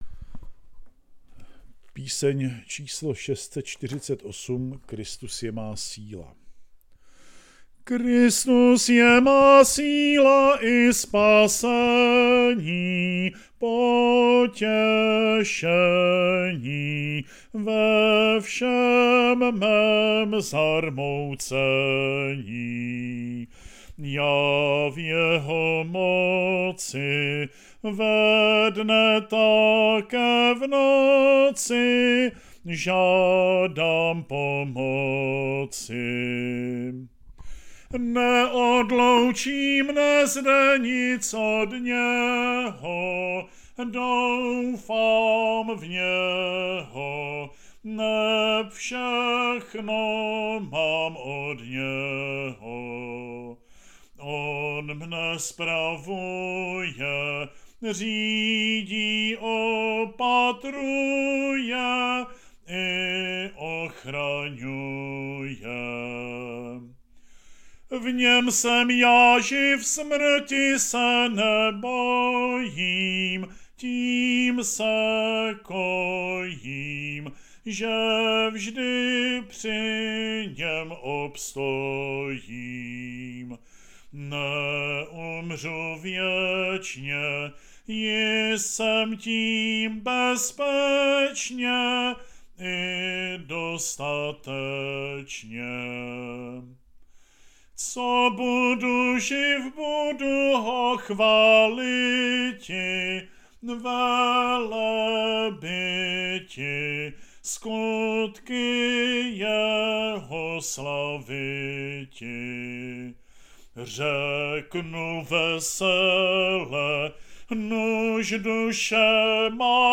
Pašijové čtení podle Matouše k poslechu a zpěvu